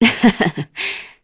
Glenwys-Vox_Happy1_kr.wav